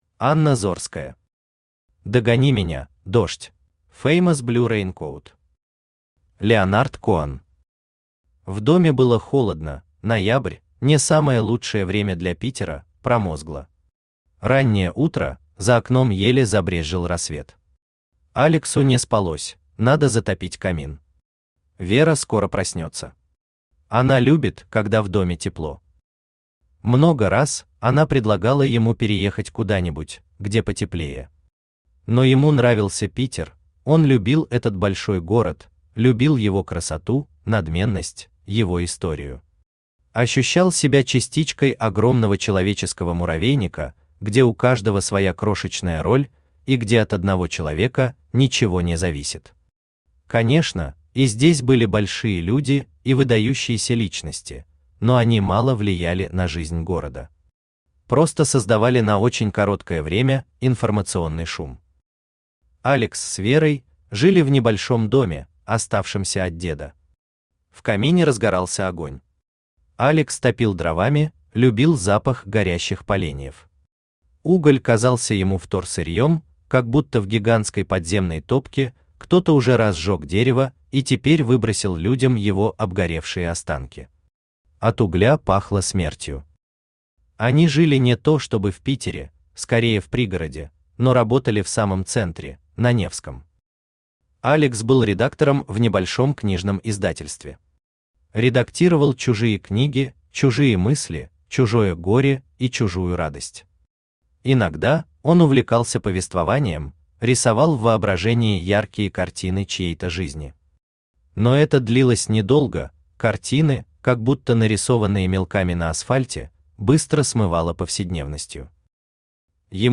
Аудиокнига Догони меня, дождь!
Автор Анна Зорская Читает аудиокнигу Авточтец ЛитРес.